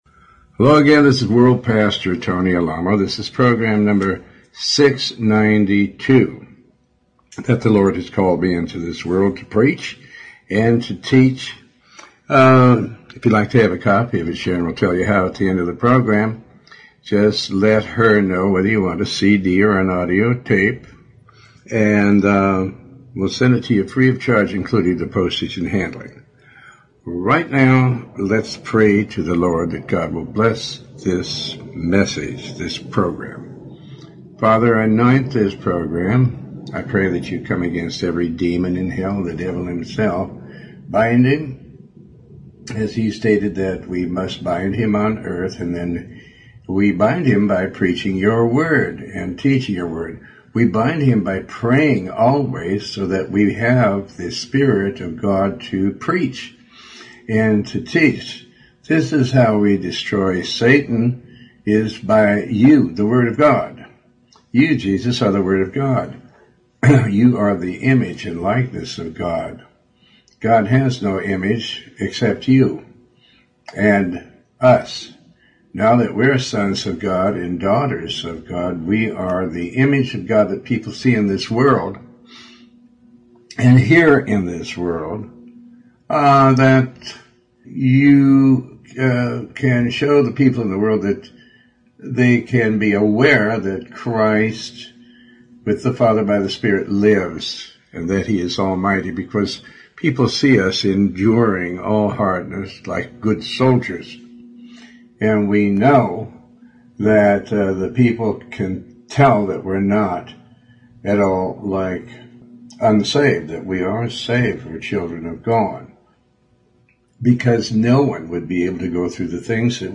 Talk Show Episode, Audio Podcast, Tony Alamo and The body that you're living in right now is not yours.